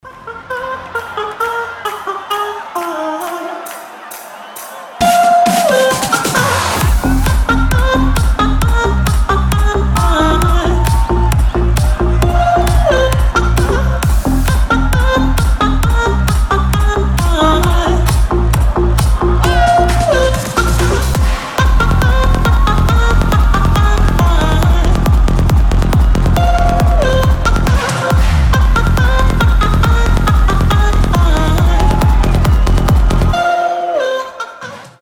• Качество: 320, Stereo
Big Room
electro house
psy-trance
live
оперный голос